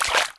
Footsteps_Water_2.ogg